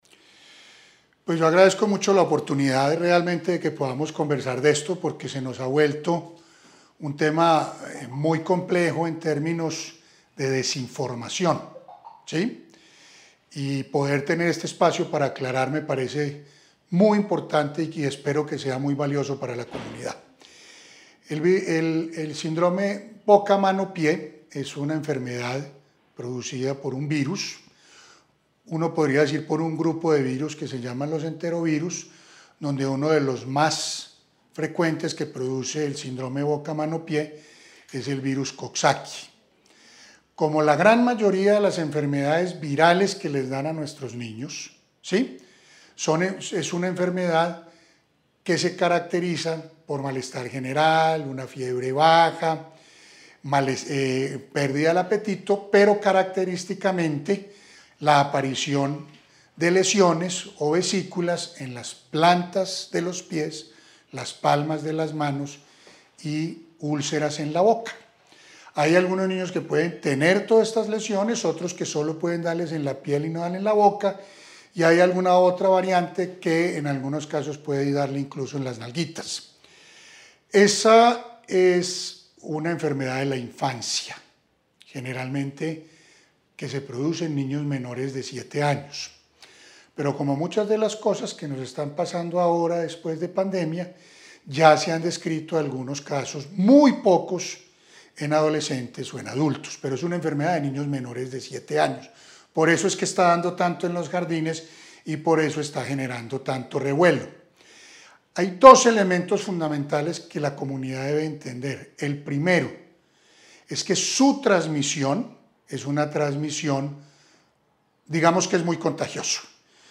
médico pediatra